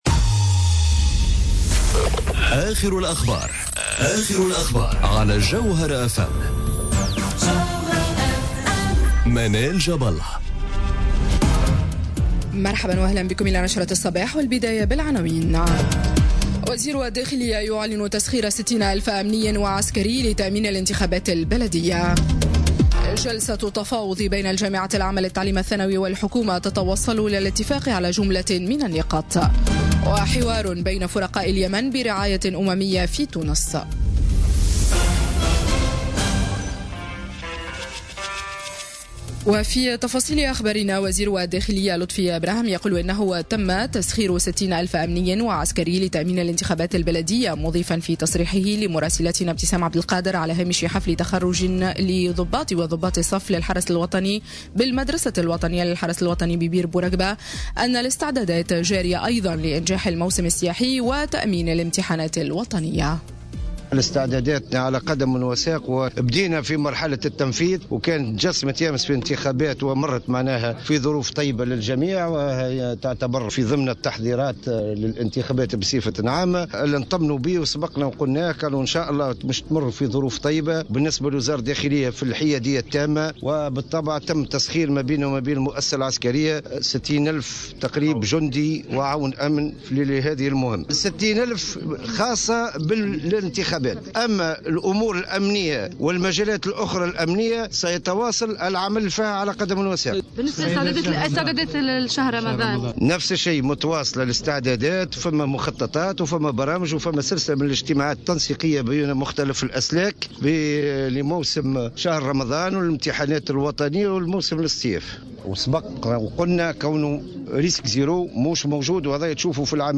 نشرة أخبار السابعة صباحا ليوم الثلاثاء 01 ماي 2018